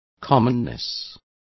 Complete with pronunciation of the translation of commonness.